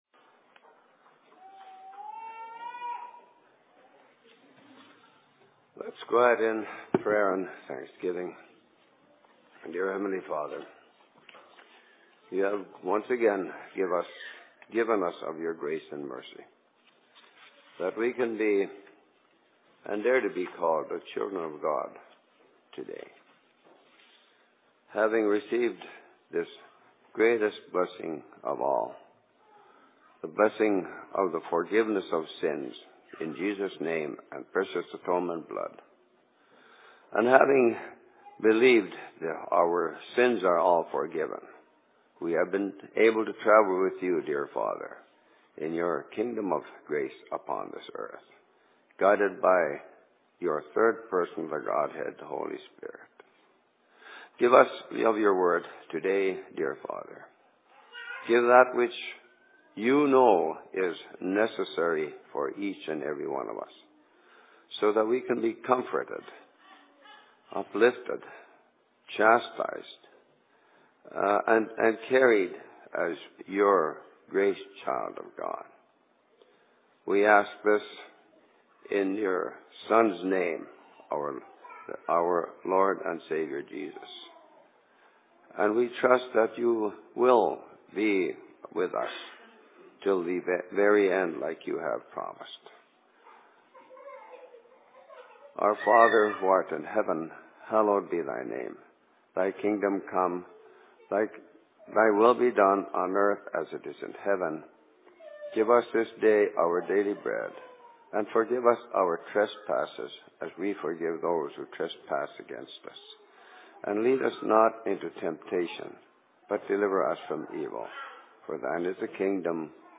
Sermon in Outlook 05.08.2007
Location: LLC Outlook